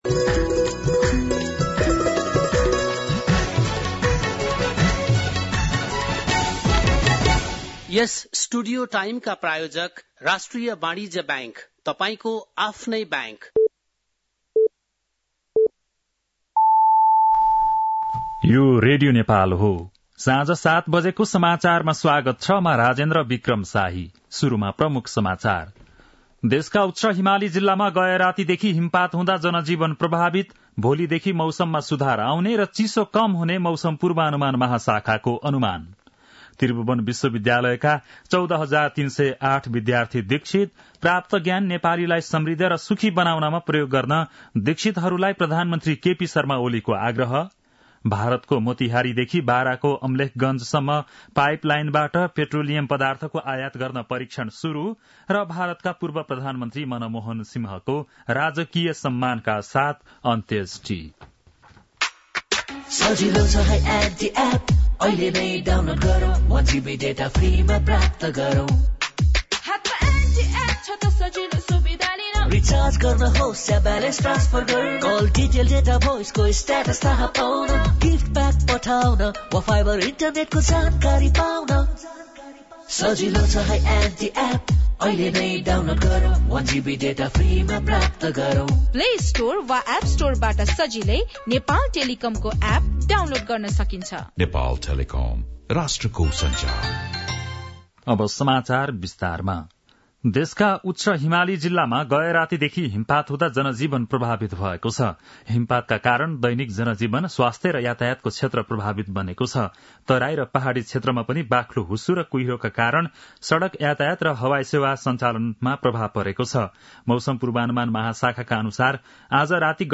बेलुकी ७ बजेको नेपाली समाचार : १४ पुष , २०८१
7-pm-news-9-13.mp3